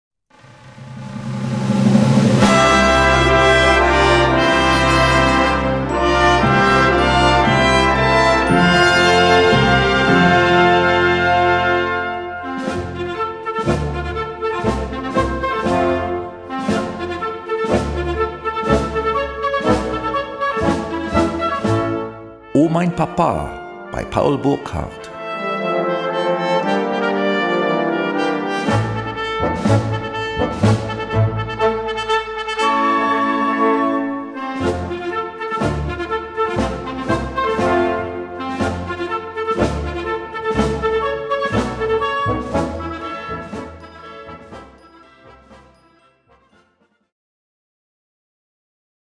Gattung: Moderne Blasmusik
A4 Besetzung: Blasorchester Zu hören auf